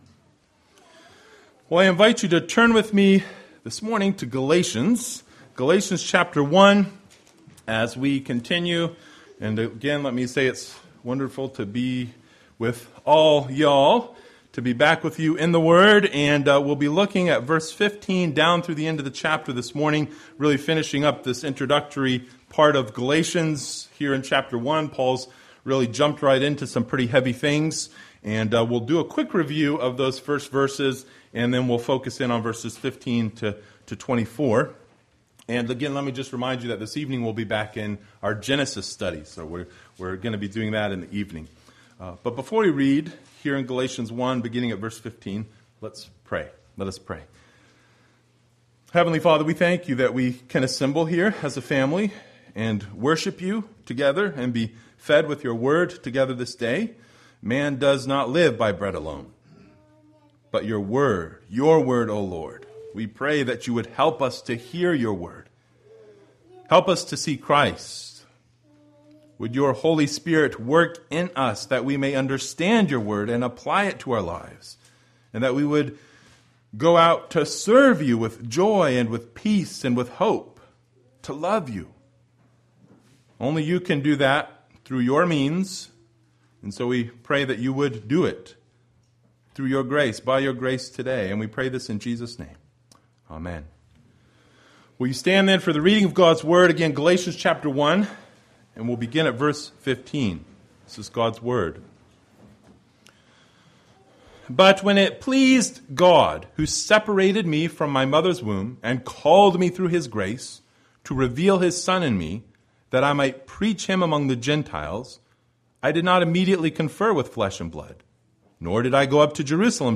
Passage: Galatians 1:15-24 Service Type: Sunday Morning